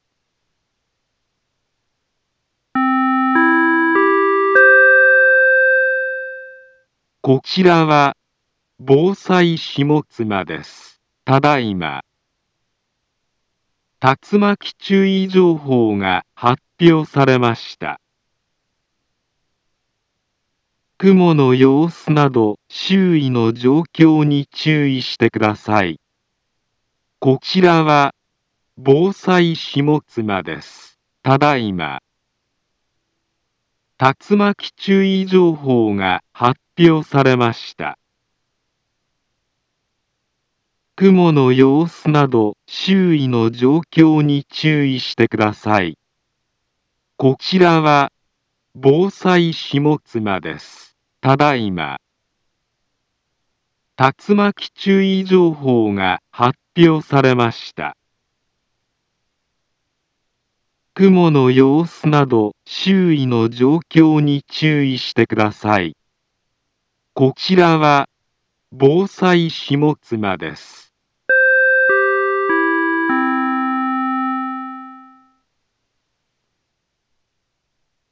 Back Home Ｊアラート情報 音声放送 再生 災害情報 カテゴリ：J-ALERT 登録日時：2023-10-15 11:39:38 インフォメーション：茨城県南部は、竜巻などの激しい突風が発生しやすい気象状況になっています。